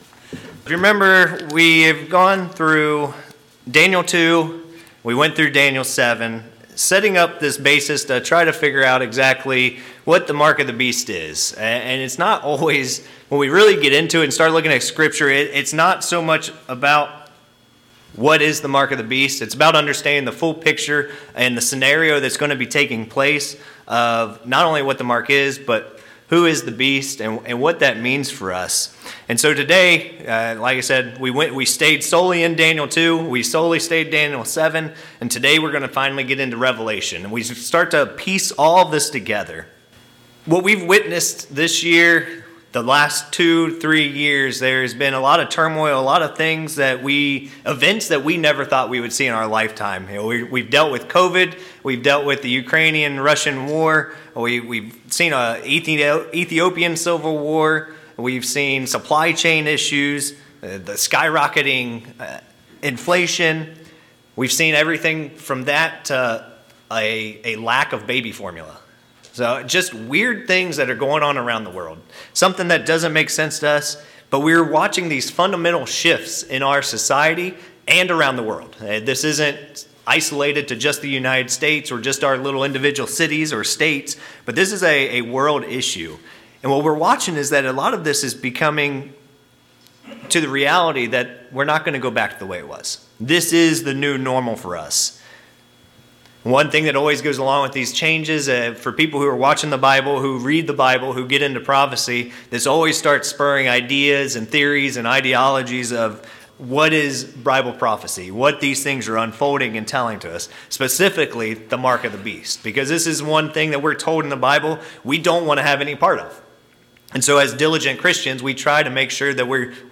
This sermon takes what we learned from Daniel and moves into Revelations to look at world events that will factor into the rise of the Beast power.